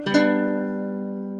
success.ogg